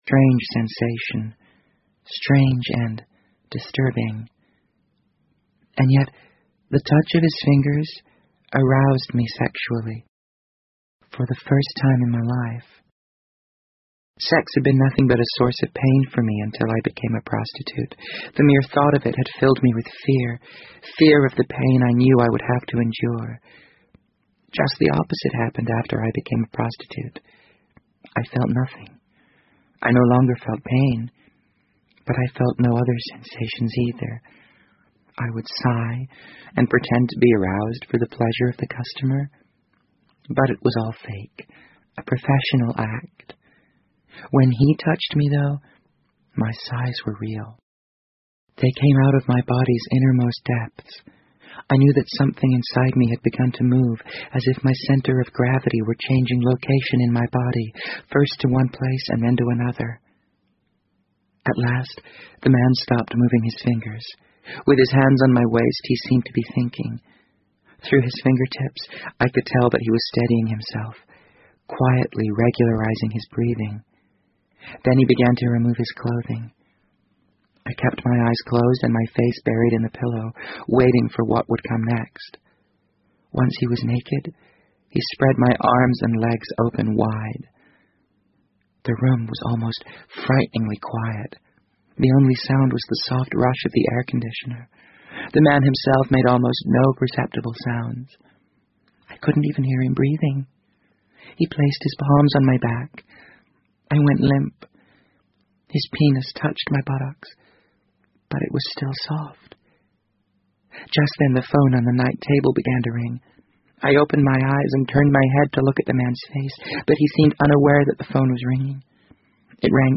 BBC英文广播剧在线听 The Wind Up Bird 008 - 7 听力文件下载—在线英语听力室